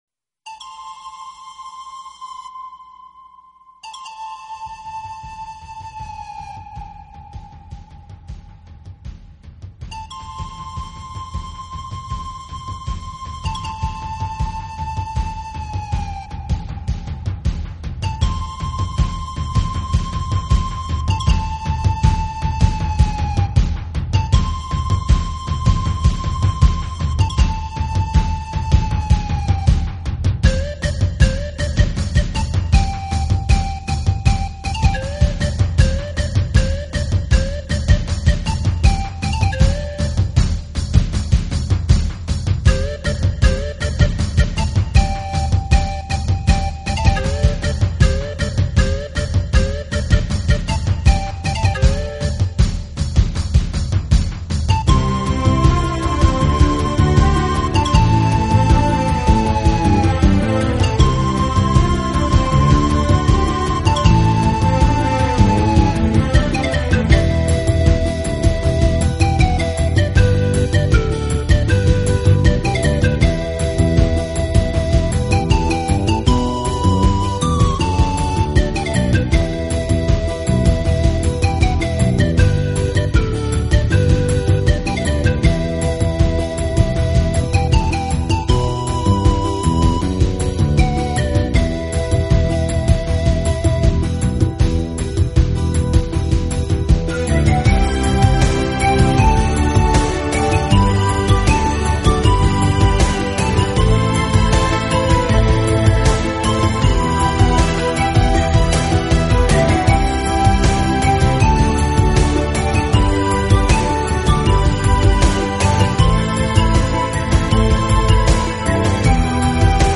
风格为南美印第安旋律